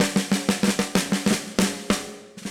AM_MiliSnareB_95-02.wav